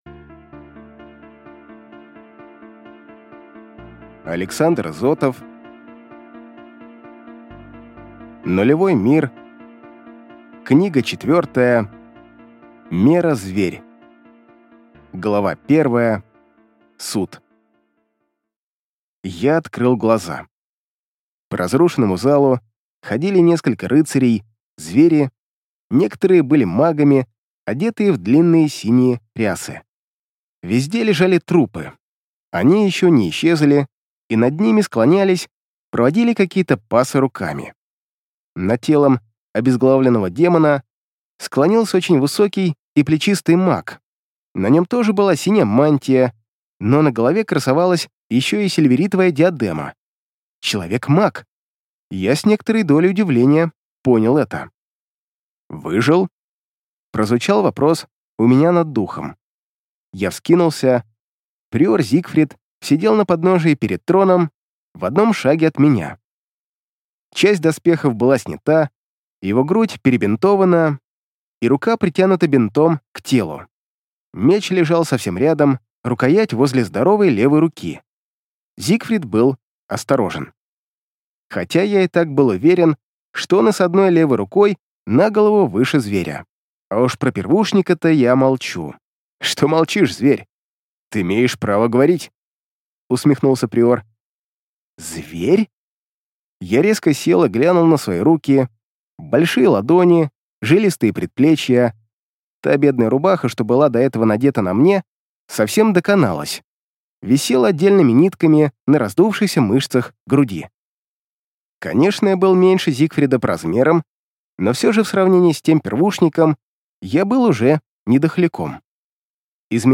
Аудиокнига Нулевой мир. Книга 4. Мера зверь | Библиотека аудиокниг